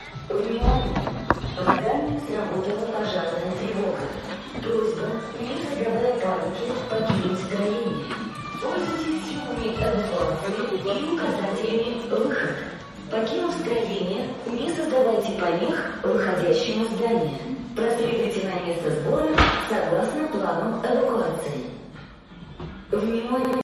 Голосовое объявление о пожаре в бассейне
Девушка его читала с серьёзным выражением.